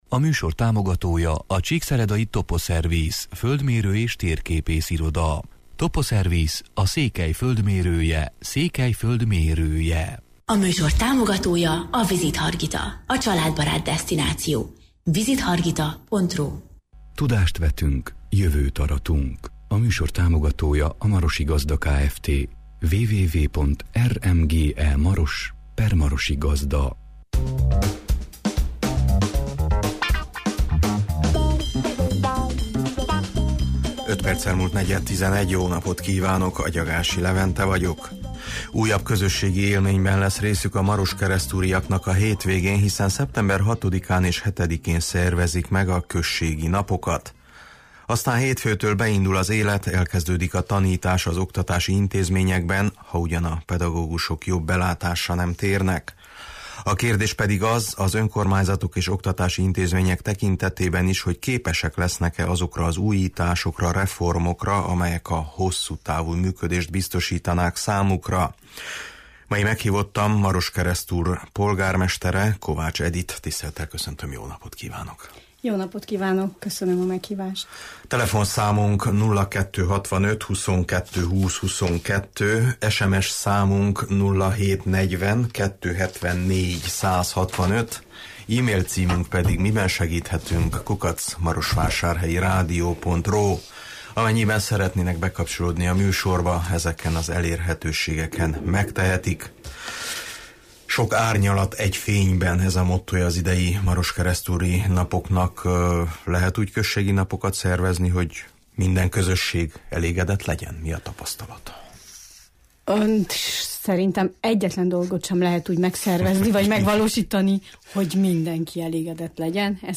Mei meghívottam Maroskeresztúr polgármestere, Kovács Edit